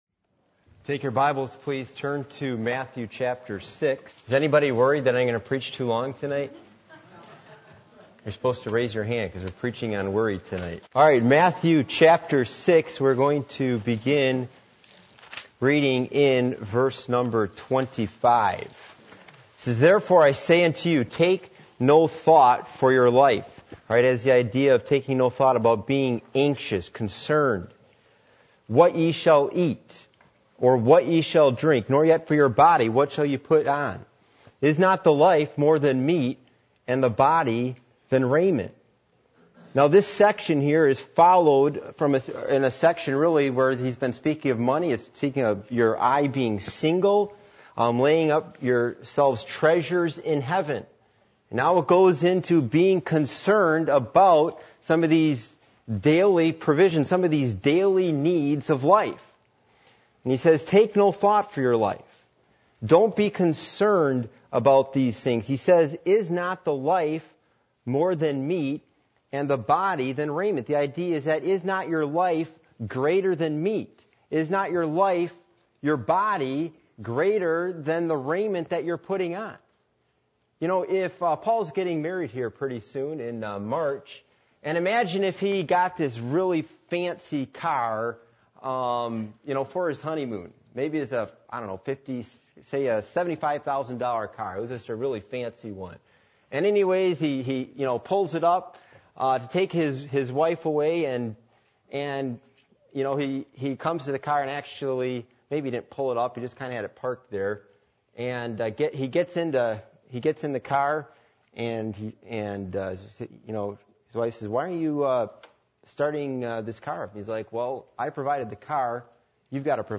Passage: Matthew 6:26-34 Service Type: Midweek Meeting